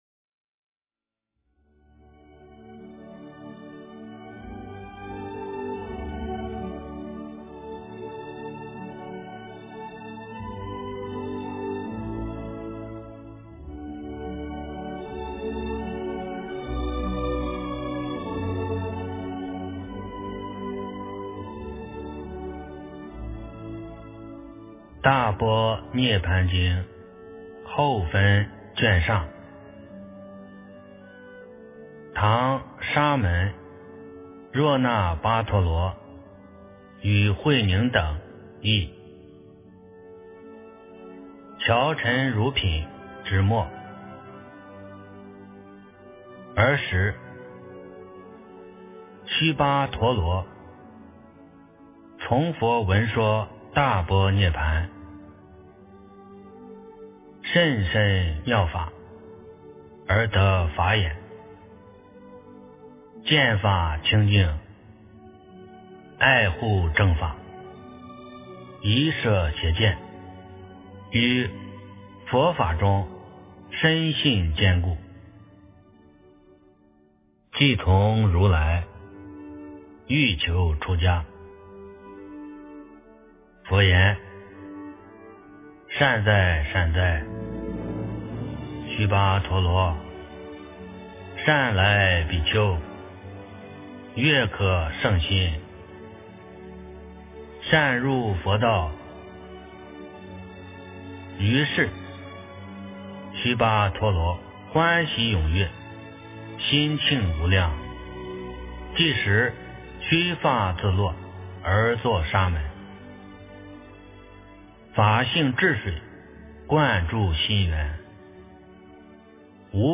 大般涅槃经41 - 诵经 - 云佛论坛